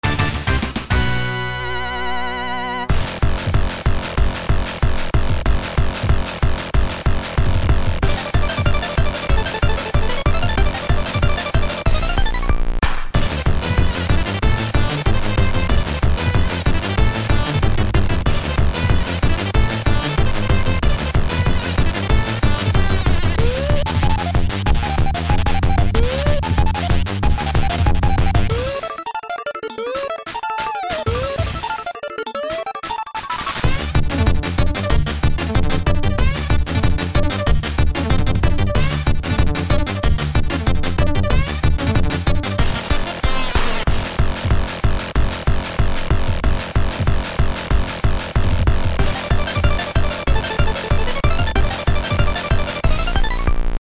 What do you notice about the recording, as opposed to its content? as heard during the boss battle or sound test